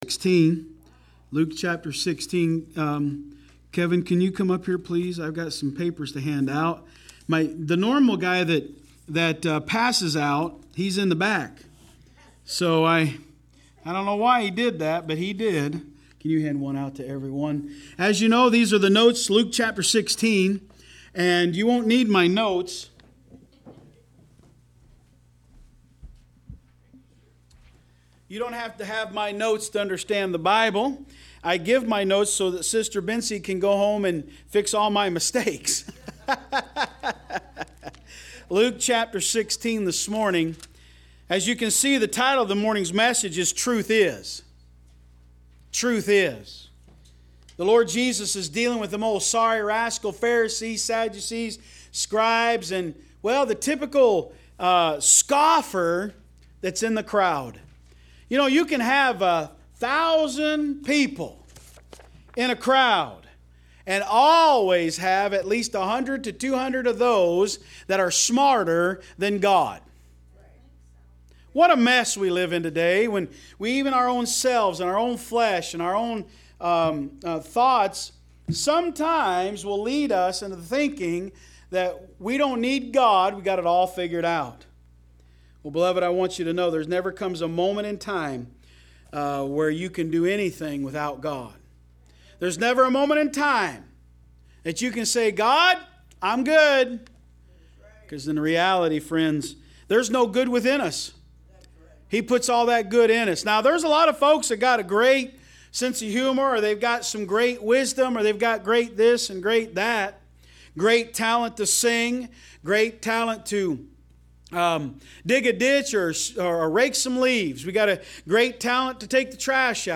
Online Sermons – Walker Baptist Church
From Series: "AM Service"